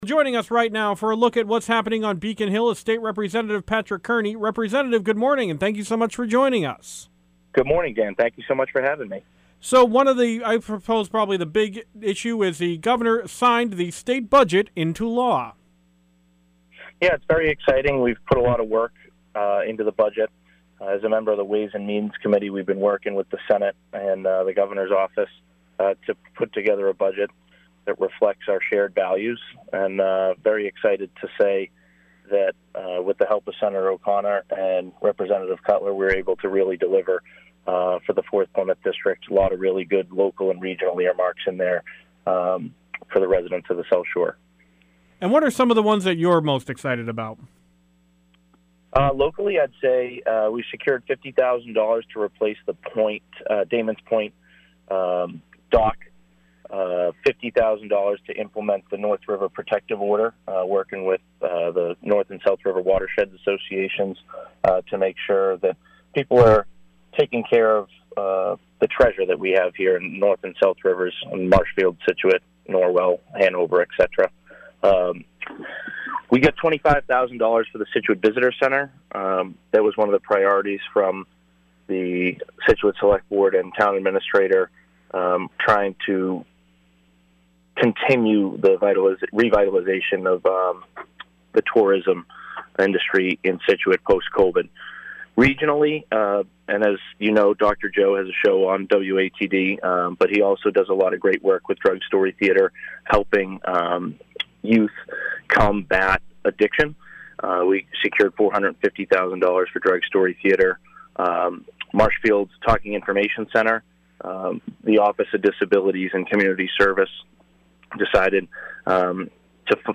— WATD 95.9 News & Talk Radio, South Shore Massachusetts